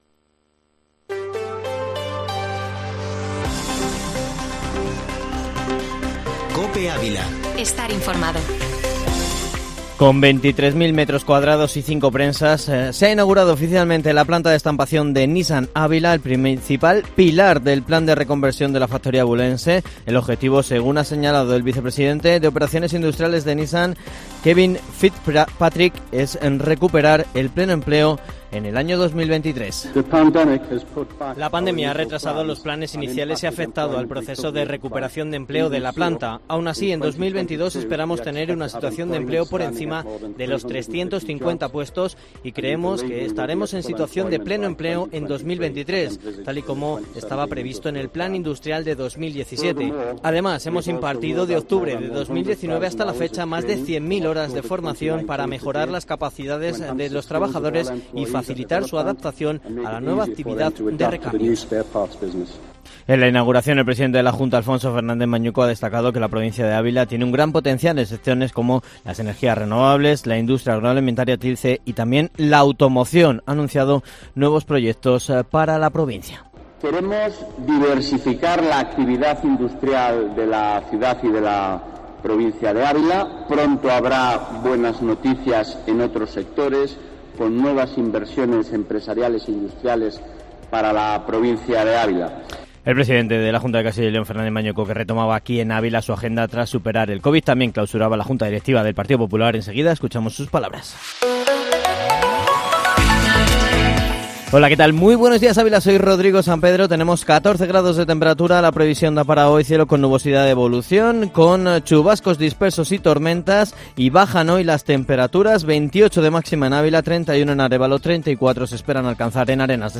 Ávila